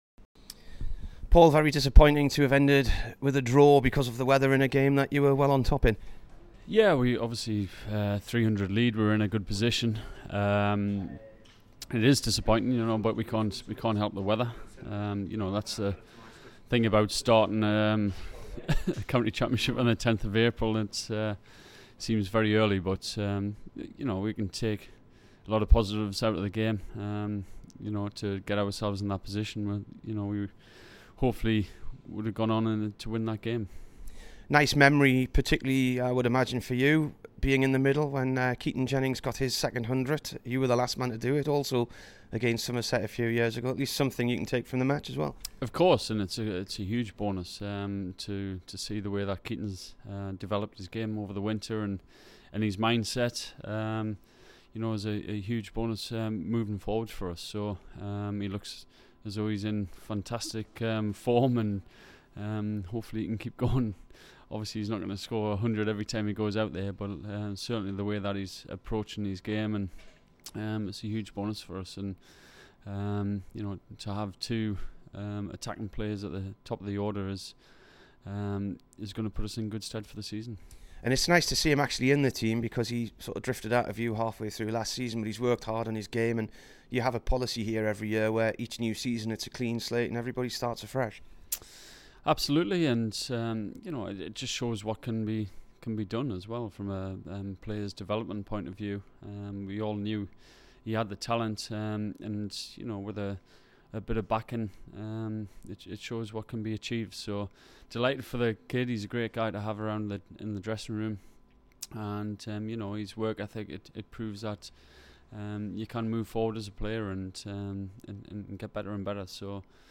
Here is the Durham skipper following the rain-affected draw with Somerset. He also speaks about Wisden Cricketer of the Year Ben Stokes.